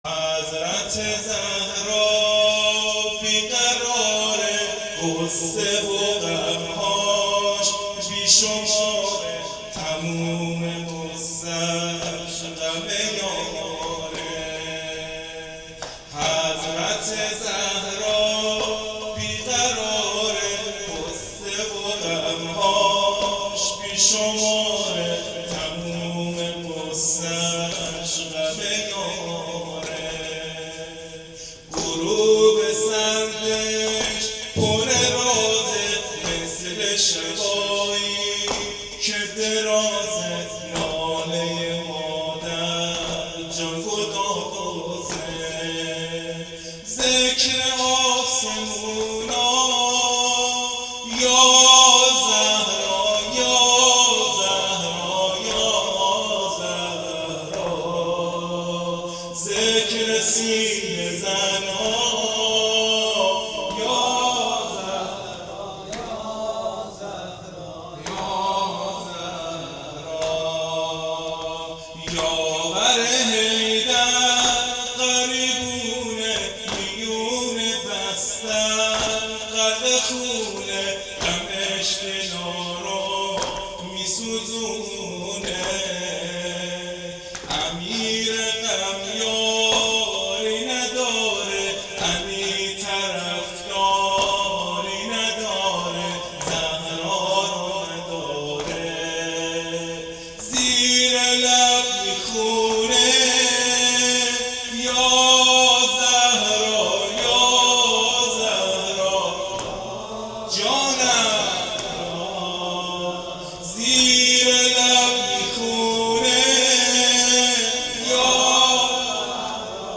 نوحه شهادت حضرت زهرا